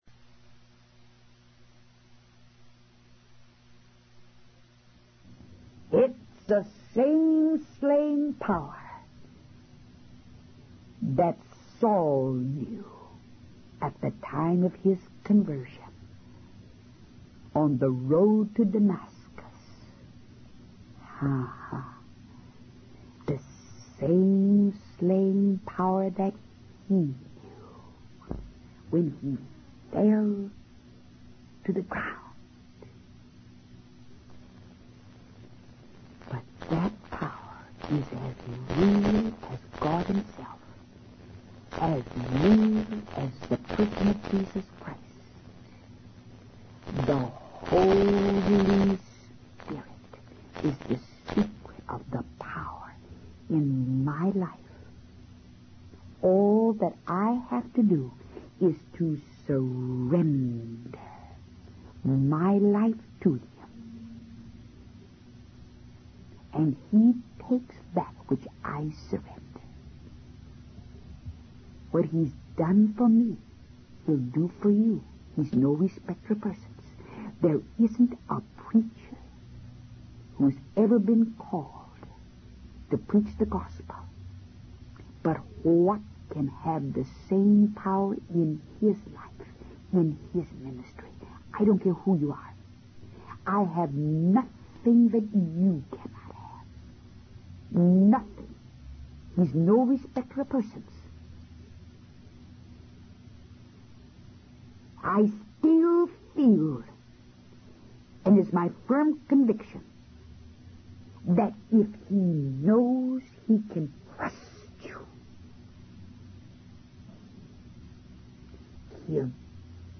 In this video, a woman from Milton, Massachusetts shares her testimony of experiencing a miraculous healing. She had been in pain for many years but during a church service, she felt a warm sensation on the side of her face that she had never felt before.